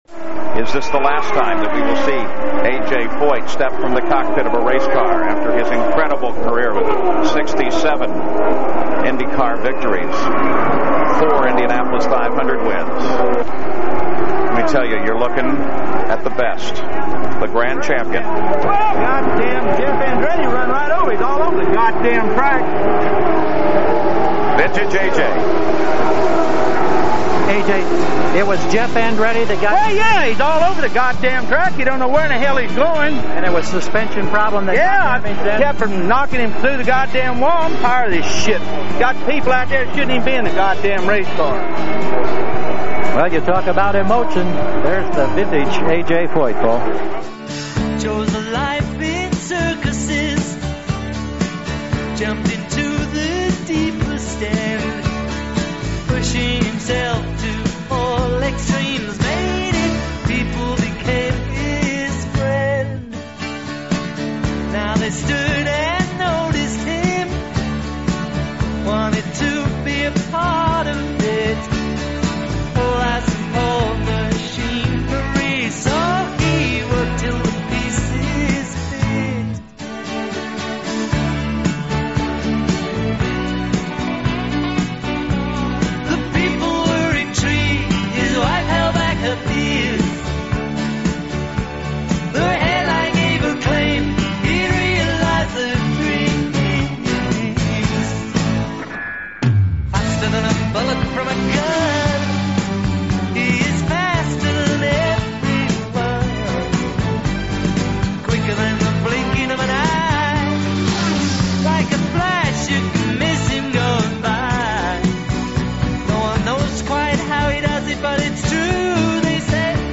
These are "mixes" I did for my own weekly Internet radio show.
"Faster" Mix featuring the drivers of Indy Car Racing